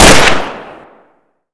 Counter-Strike Pack / Original Sounds / weapons / fiveseven-1.wav
fiveseven-1.wav